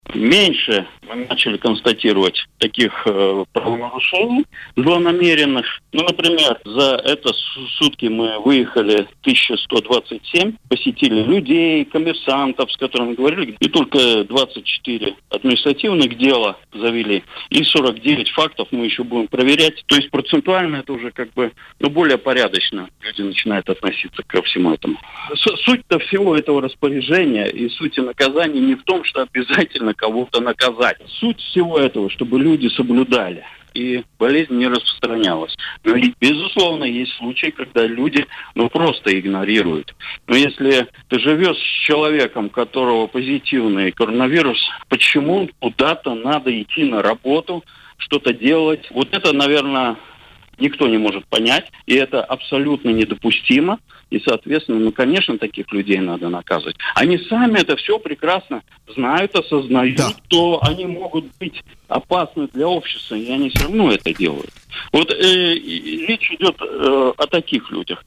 Жители Латвии стали серьезнее относиться к ограничительным мерам из-за угрозы распространения коронавируса. Об этом в эфире радио Baltkom рассказал исполняющий обязанности начальника Госполиции Андрей Гришин.